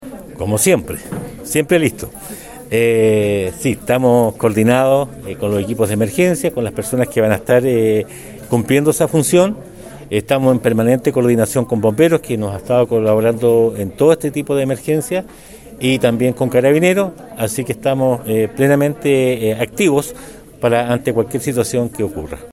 Ante esta alarma, el Alcalde de Osorno Emeterio Carrillo, señaló que todos los equipos de emergencia se encuentran preparados para atender cualquier requerimiento de los vecinos ante el sistema frontal